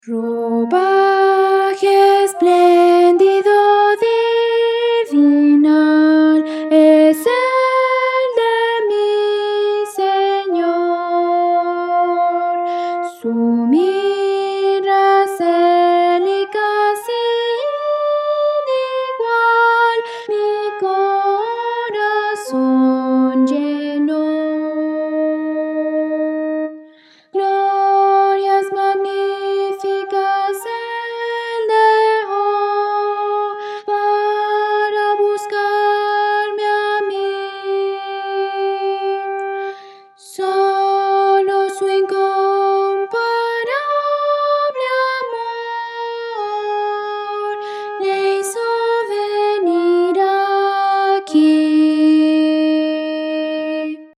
Voces para coro
Soprano
Audio: MIDI